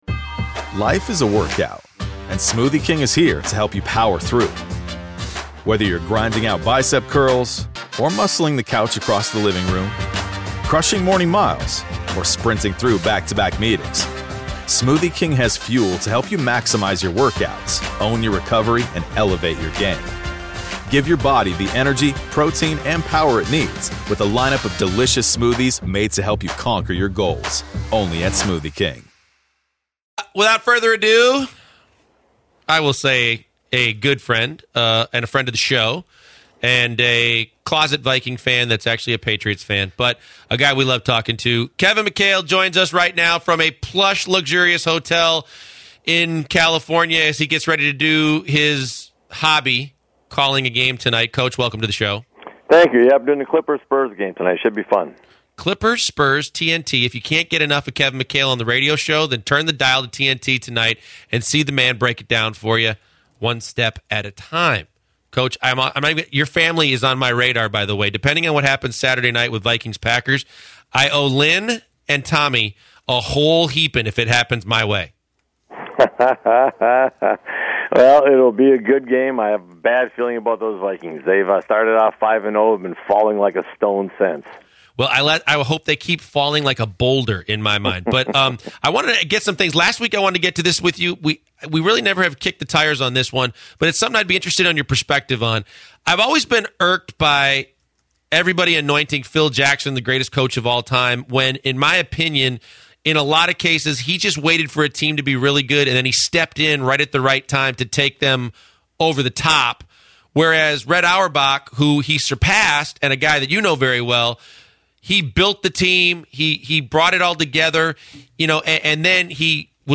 former Houston Rockets head coach Kevin McHale on via telephone